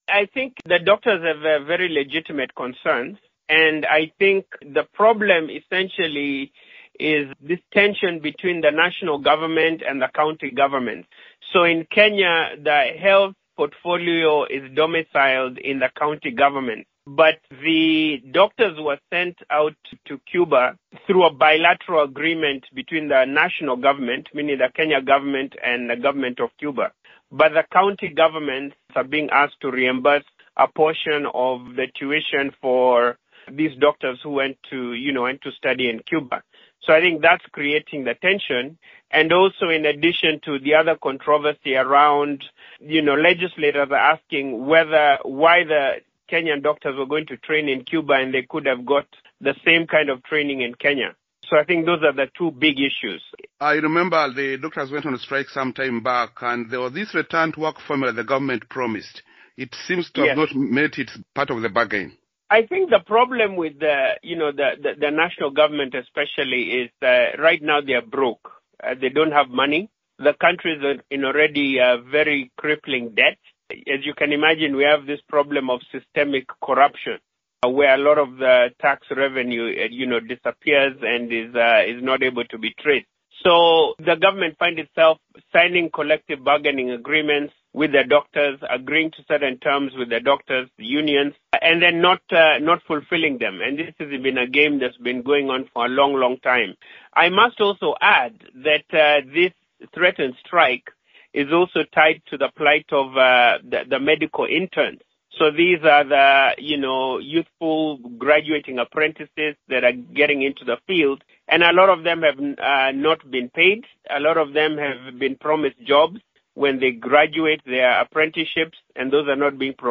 Political analyst